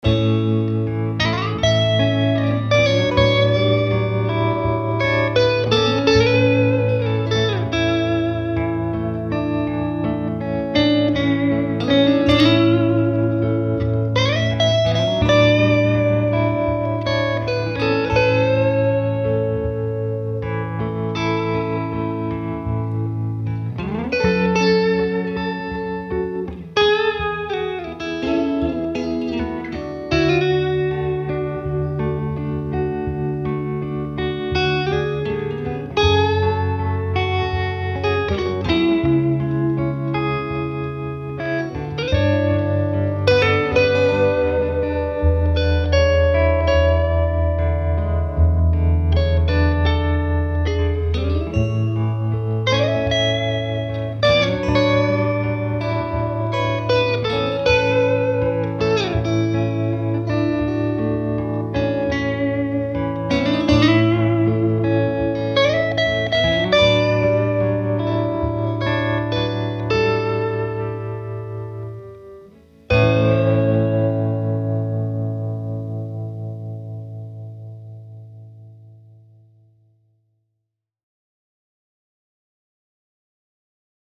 • This next song is called “Plexi Lullaby” because it reminded me of a lullaby. The base rhythm track was recorded on the tremolo channel with my Heritage, then I created a second rhythm track with my Strat. The Lead is also played with my Strat. You’ll notice that you really have to listen for the trem. The tube-driven trem is killer. It’s very subtle and oh so smooth!
The base rhythm track was played through the stock speaker, while the Strat parts were recorded through a P12N, and no EQ was applied to any of the parts.